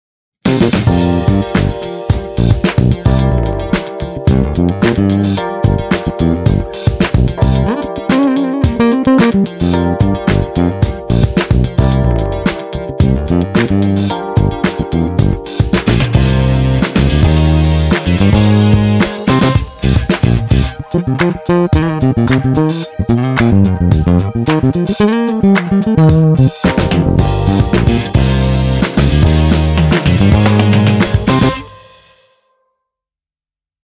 永恒的电贝司
源自 Scarbee 的采样电贝司
可用于流行、都市、摇滚、disco 等多种风格
忠实还原 FENDER® PRECISION BASS
SCARBEE PRE-BASS 使用了圆卷弦进行录制，以获得肥厚粗糙的音色，除此以外，还录制了成系列的大量演奏技巧。